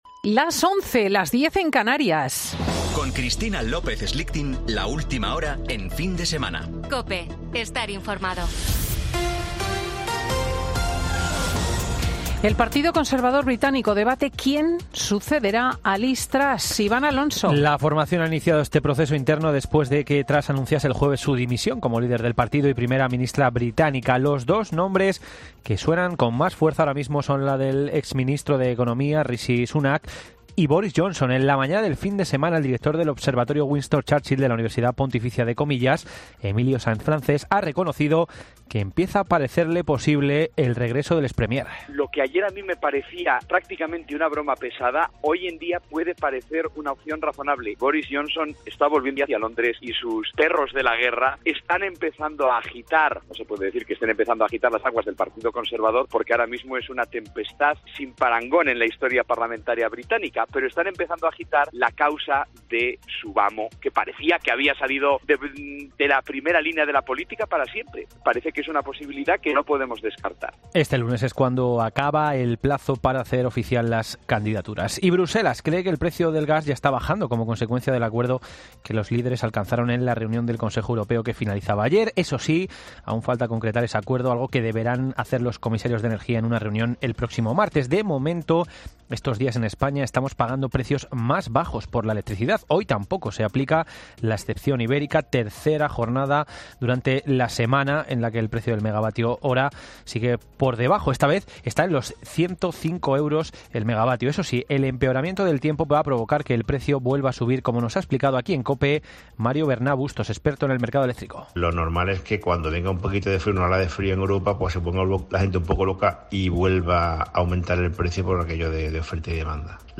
Boletín de noticias de COPE del 22 de octubre de 2022 a las 11.00 horas